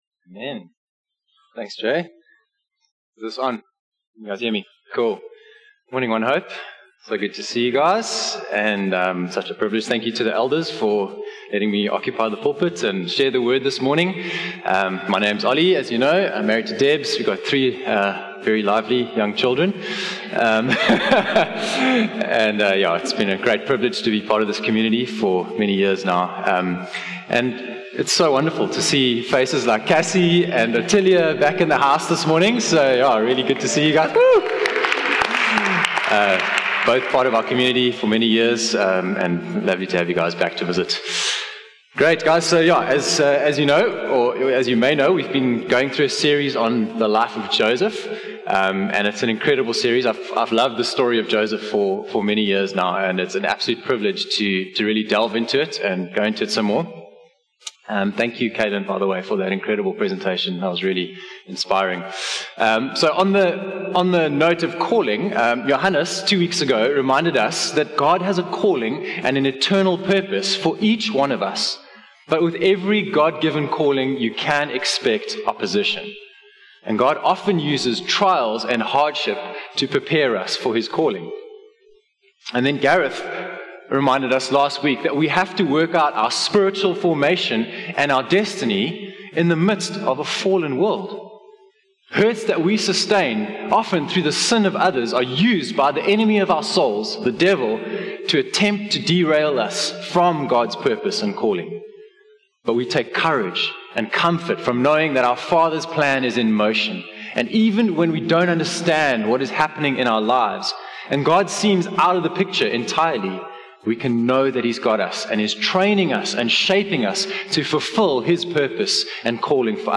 One-Hope-Sermon-8-September-2024.mp3